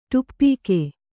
♪ tuppīke